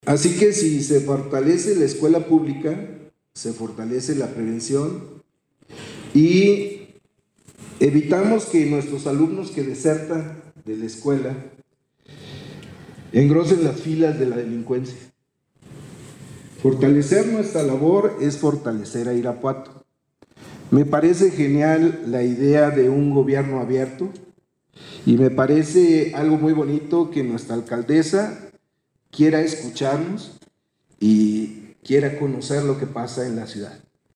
AudioBoletinesFeria de las Fresas
Lorena Alfaro García, Presidenta de Irapuato